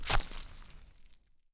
Syringe2.wav